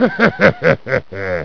Creepy Laugh 8 Sound Effect Free Download
Creepy Laugh 8